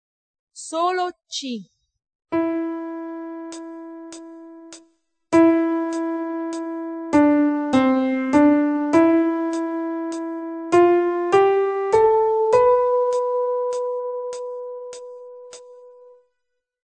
Dove il metronomo fa ascoltare le suddivisioni, e non le unità di tempo.
Nel 9/8 i due tap e lo slancio rappresentano il  3° tempo della misura.